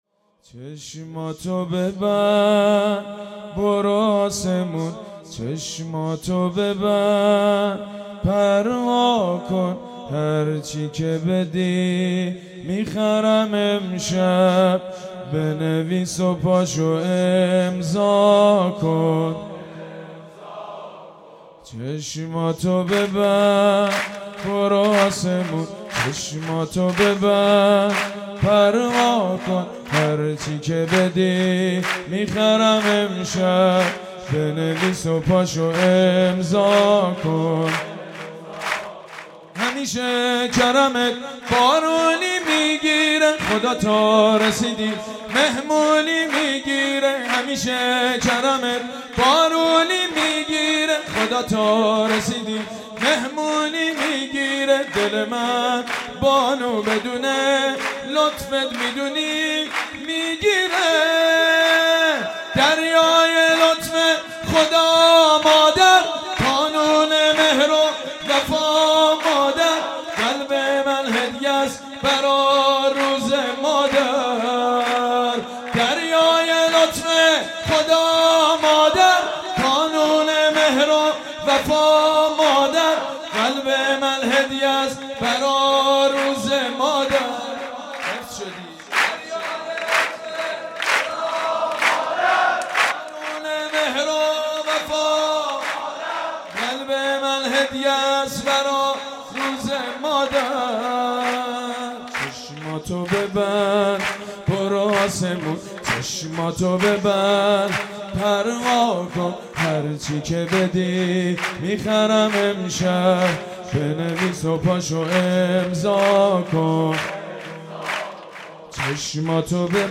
مولودی بسیار زیبا حضرت زهرا (س)
این مولودی‌ها بیشتر به صورت تک‌خوان و آهنگین اجرا شده است.